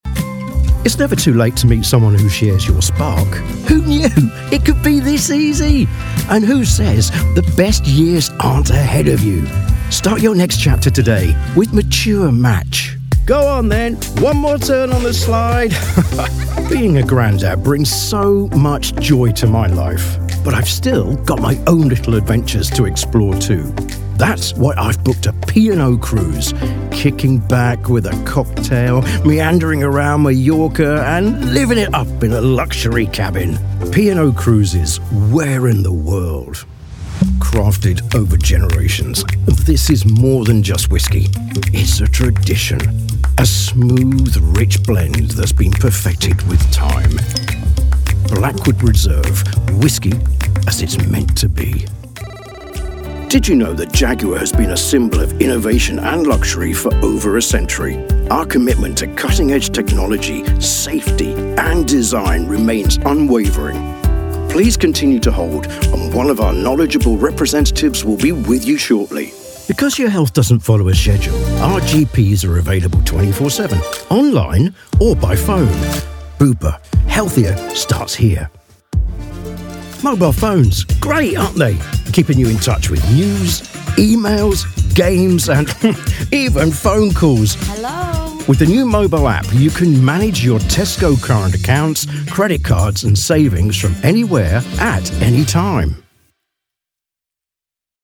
Kind, paternal, dynamic and jovial voice. Captivating and energetic for corporate recordings, as well as to give identity to your service or product....
0618Advertising_corporate.mp3